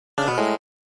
Wrong Answer.mp3